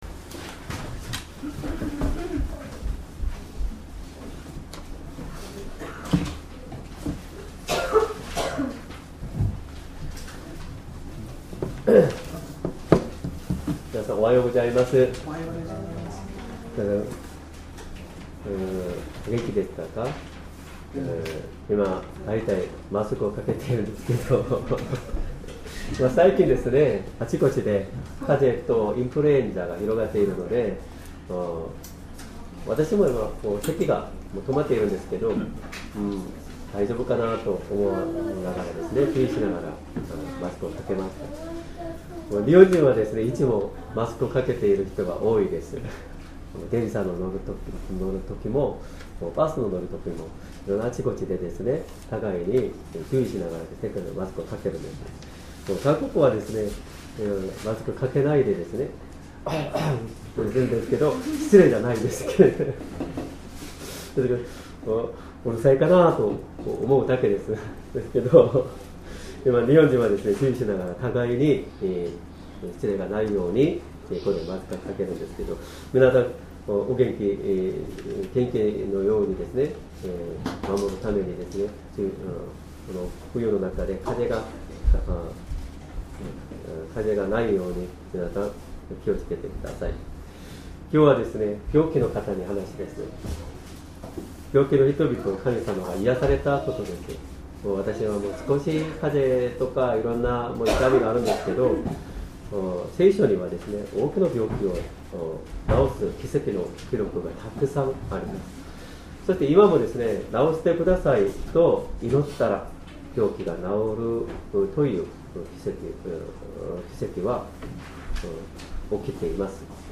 Sermon
2025年1月26日 主日礼拝 説教 「病気の人々を癒されたイエス様」 聖書 マタイ 8:1-13 8:1 イエスが山から下りて来られると、大勢の群衆がイエスに従った。